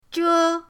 zhe1.mp3